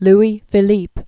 (lē fĭ-lēp, l-ē fē-lēp) Known as "the Citizen King." 1773-1850.